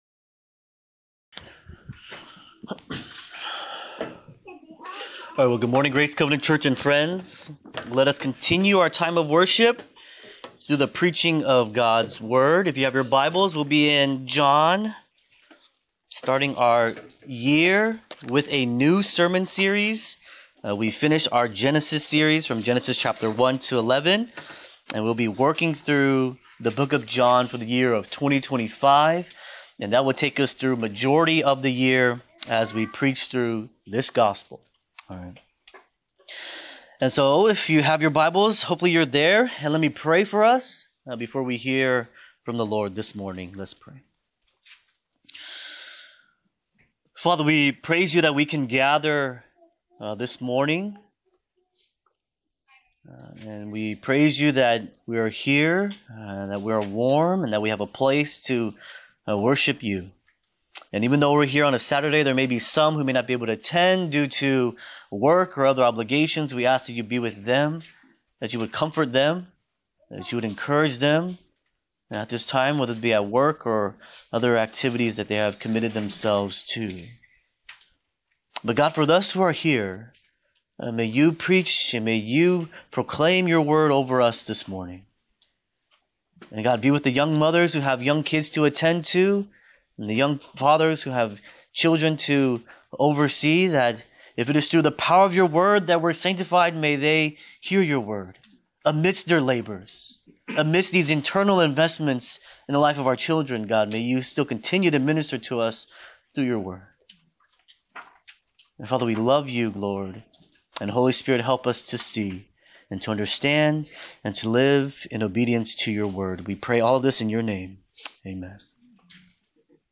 Sermons | Grace Covenant Church